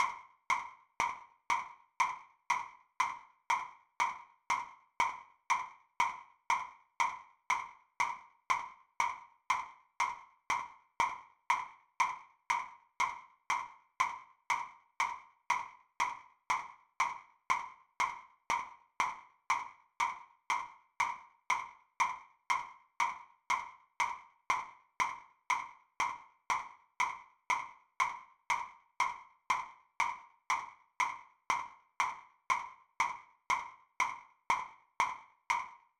click track
eine-kleine-click.wav